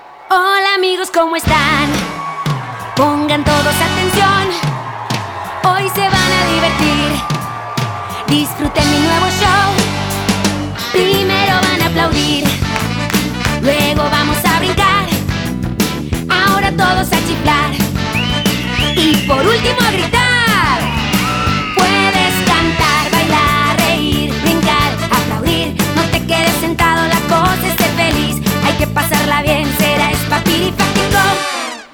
In 2007, she released another new children's album.